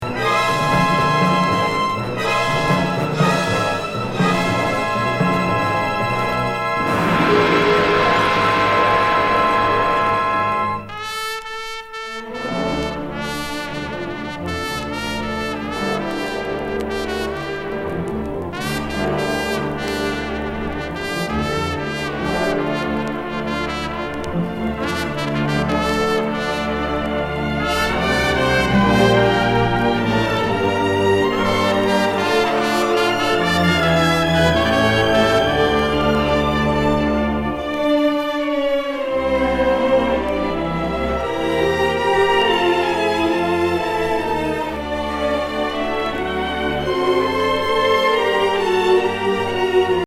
国産ゴッドファーザーなスリリング・ファンキー・オーケストラ
＋台詞入り！